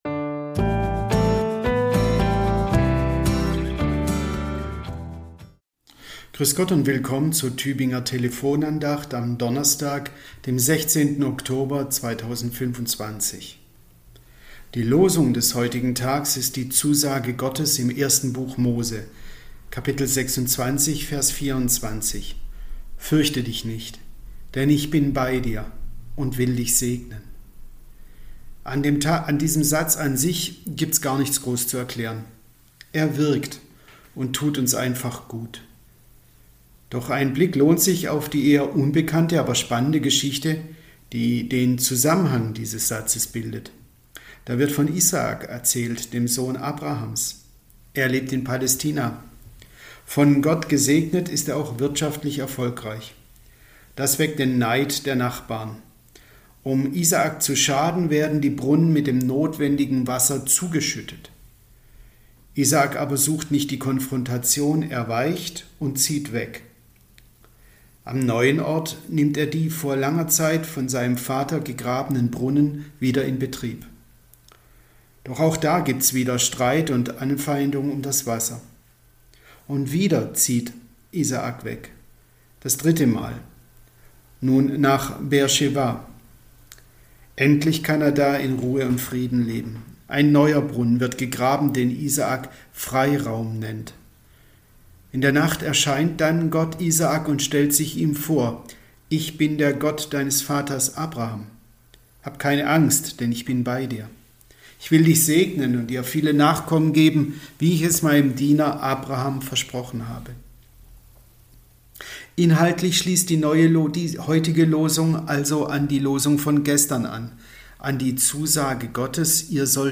Andacht zum Wochenspruch